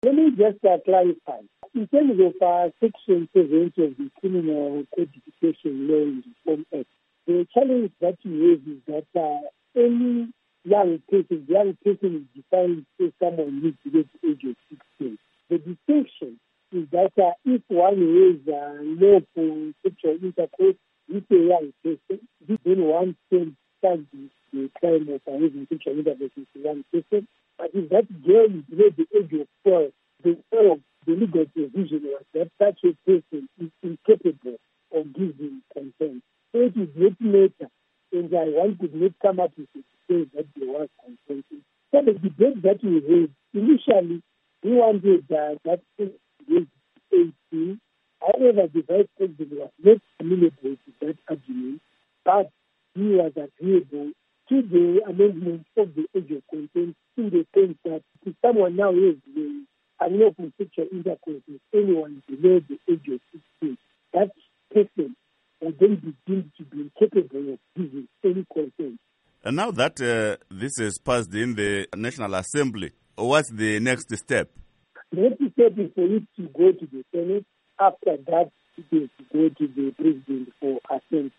Interview With Innocent Gonese